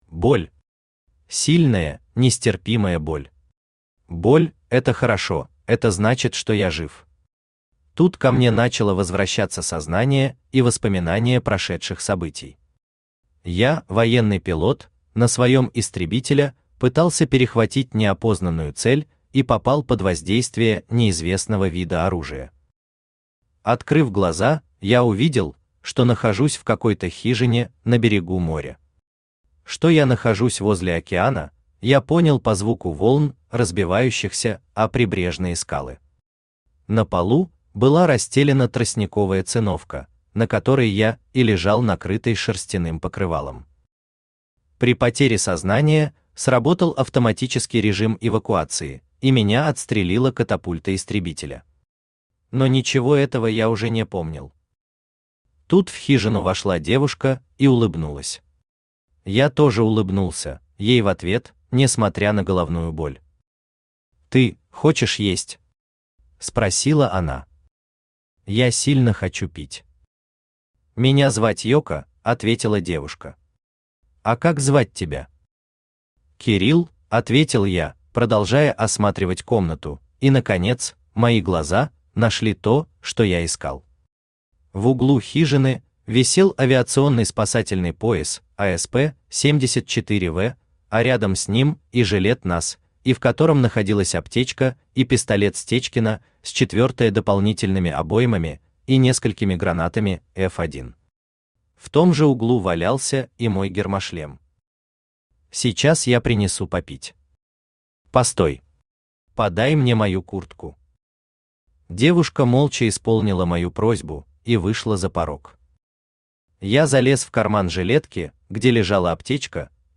Аудиокнига Сбитый лётчик | Библиотека аудиокниг
Aудиокнига Сбитый лётчик Автор Дмитрий Обской Читает аудиокнигу Авточтец ЛитРес.